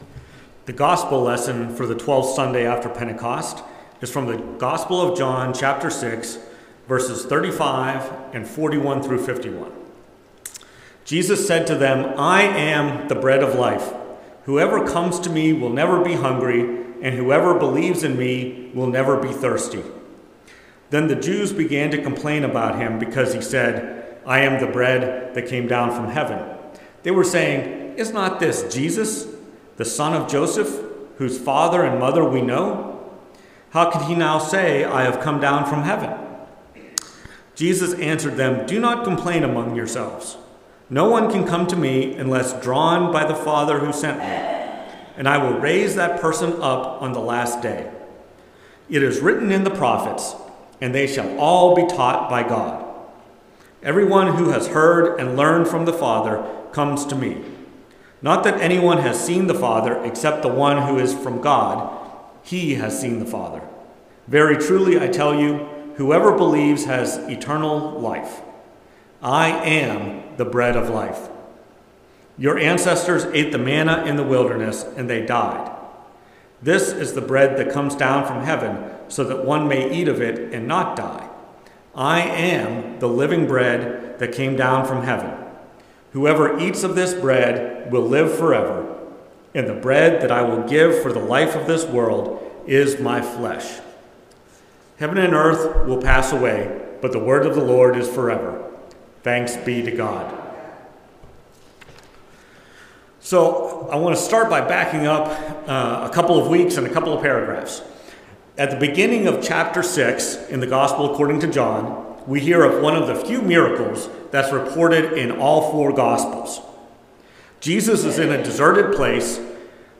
Preached at First Presbyterian Church of Rolla on August 25, 2024. Based on John 6:56-69.